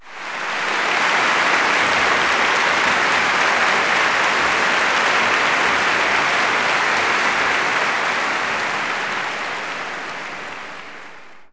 clap_033.wav